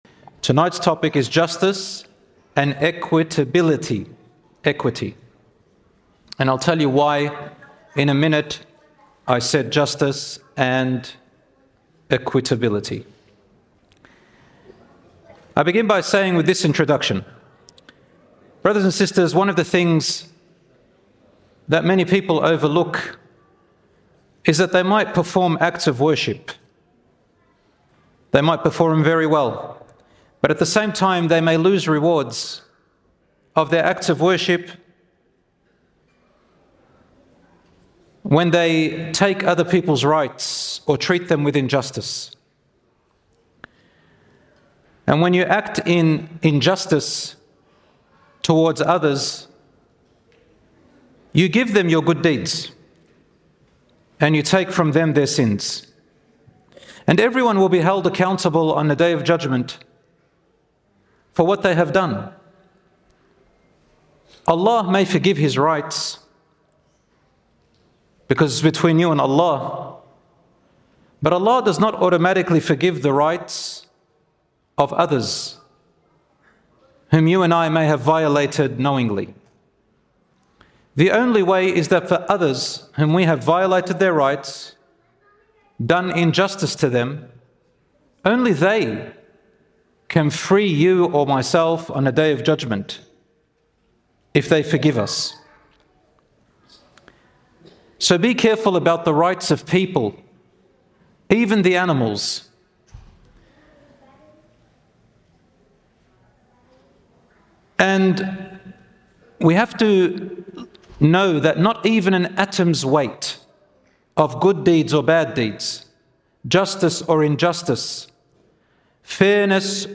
In this talk, we reflect on how Islam teaches us to stand firm for justice, even when emotions, loyalty, or personal gain tempt us to do otherwise.